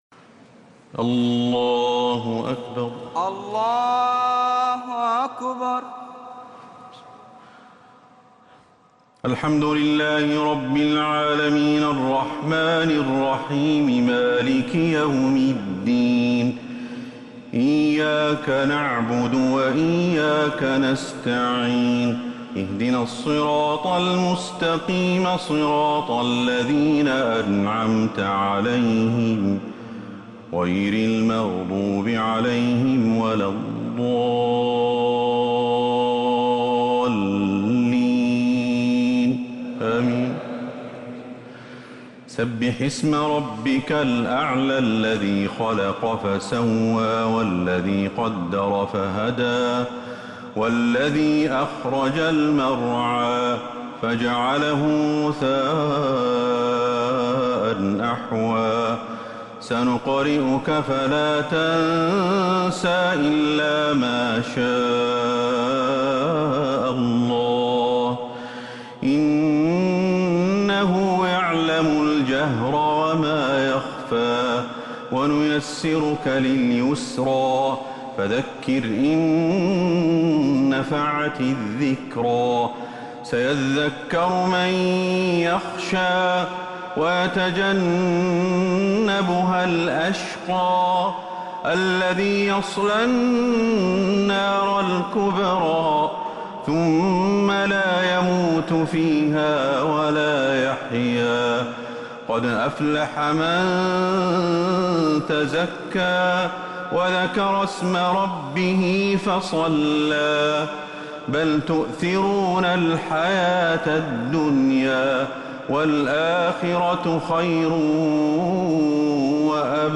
الشفع و الوتر ليلة 23 رمضان 1447هـ | Witr 23rd night Ramadan 1447H > تراويح الحرم النبوي عام 1447 🕌 > التراويح - تلاوات الحرمين